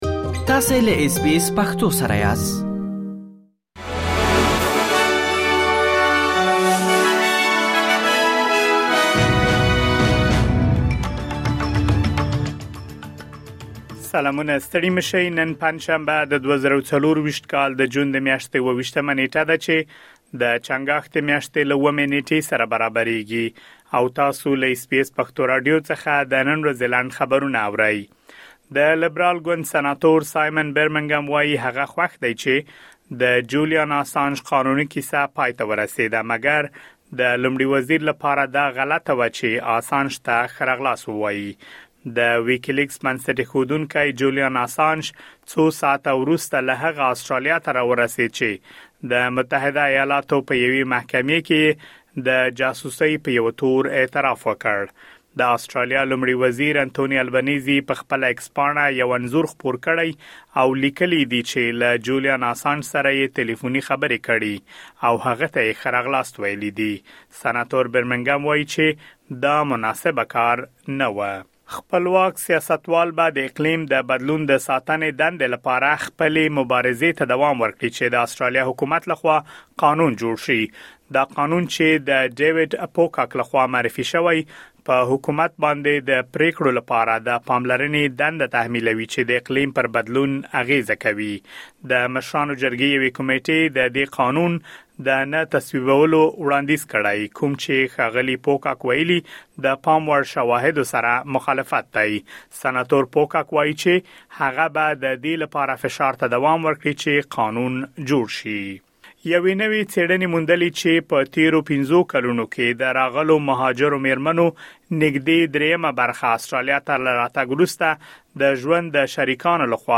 د اس بي اس پښتو د نن ورځې لنډ خبرونه|۲۷ جون ۲۰۲۴